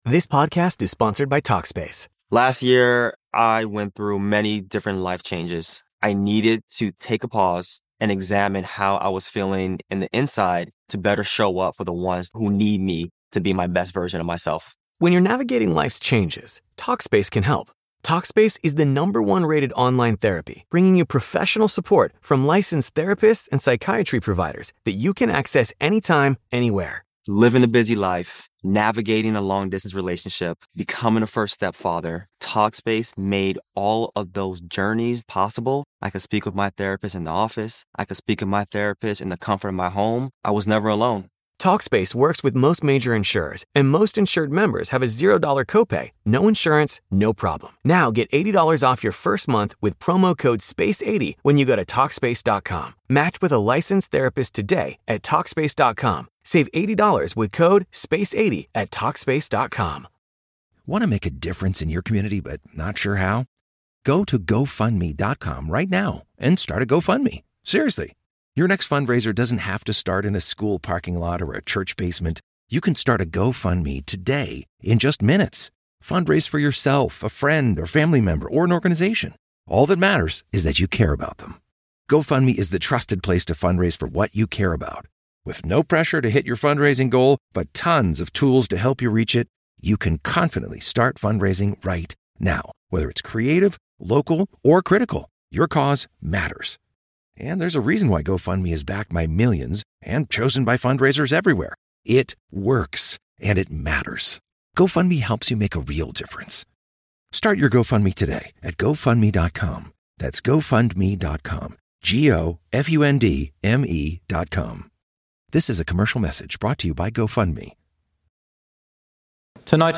In this lecture, we explore the concept of spiritual heedlessness (ghaflah) in Islam and emphasise staying spiritually aware by remembering Allah through Quran recitation, prayer, good company, and practical daily habits that keep us focused on our purpose.